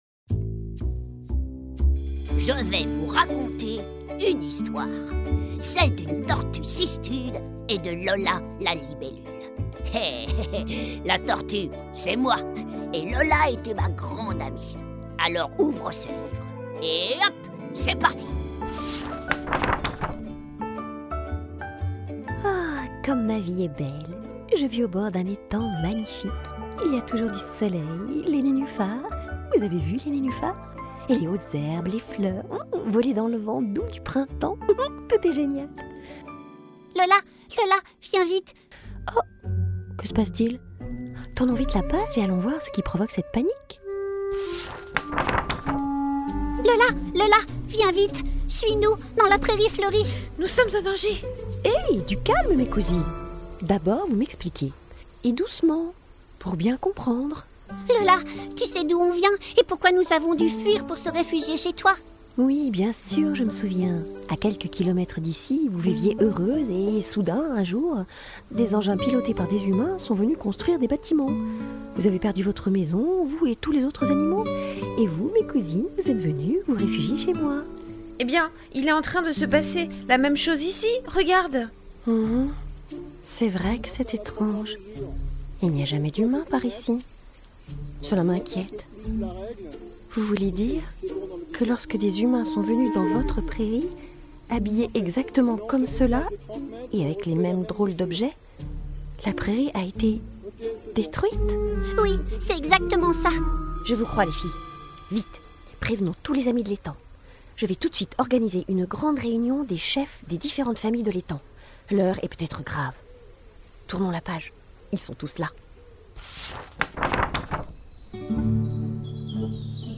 L'échappée belle de Emys et Lola, un audio livre pour les enfants de 3 à 7 ans